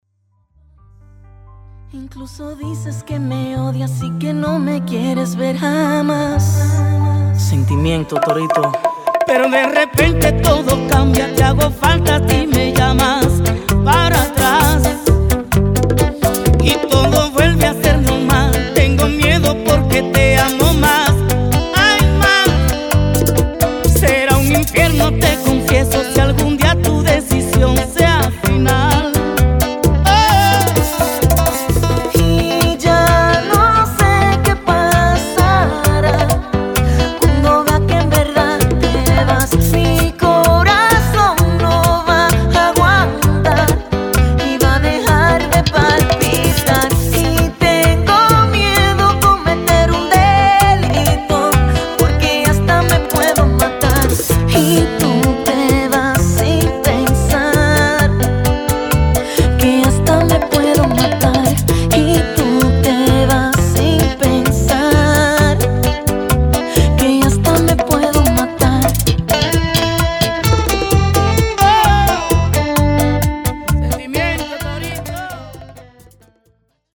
Bachata
Ritmul de Bachata este de 4/4 cu miscari de bazin accentuate si coregrafii ce denota romantism.
bachata.mp3